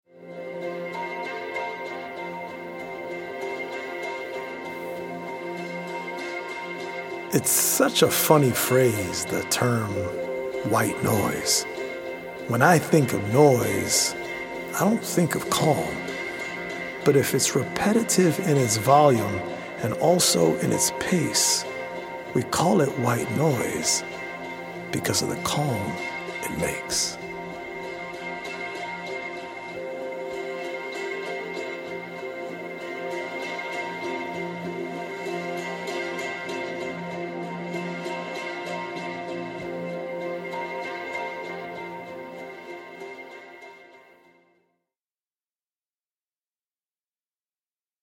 healing audio-visual poetic journey
healing Solfeggio frequency music
EDM producer